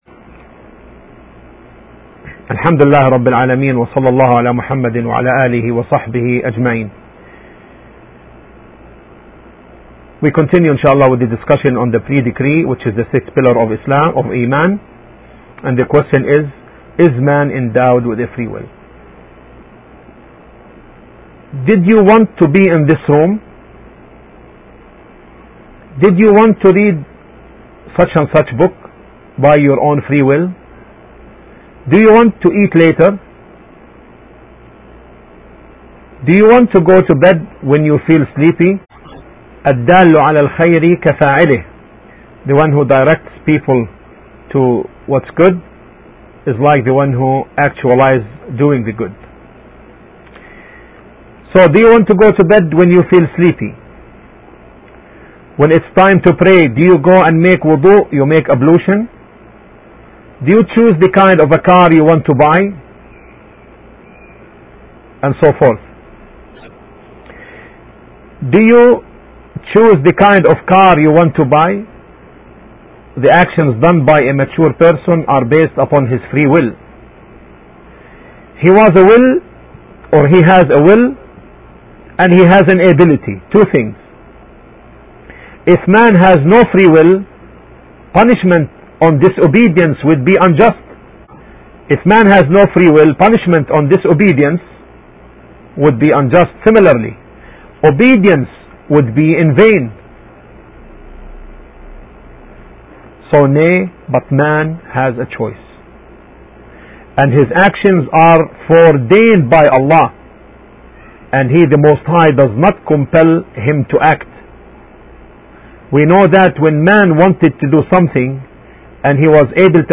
Pre-decree of Allah has four ranks: knowledge, writing, willing and creation. The lecturer answers an important question: